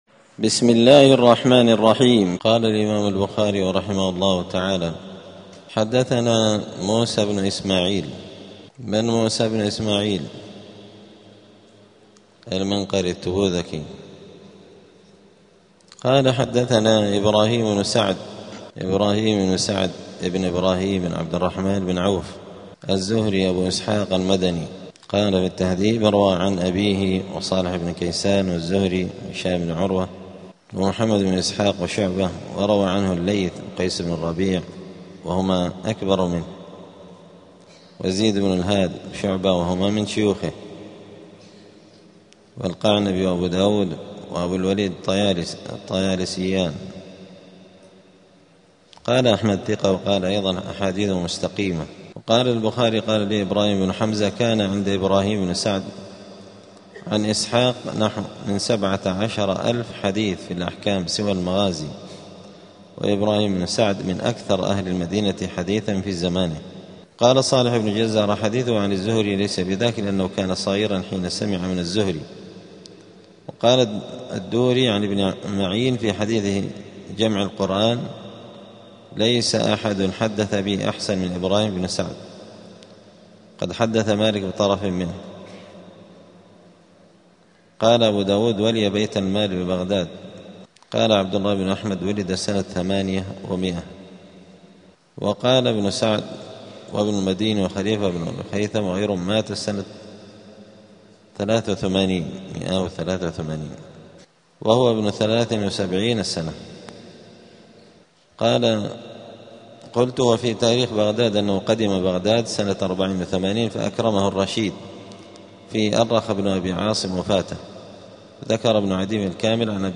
دار الحديث السلفية بمسجد الفرقان قشن المهرة اليمن
الأحد 16 جمادى الآخرة 1447 هــــ | الدروس، دروس الحديث وعلومه، شرح صحيح البخاري، كتاب الحرث والمزارعة من صحيح البخاري | شارك بتعليقك | 6 المشاهدات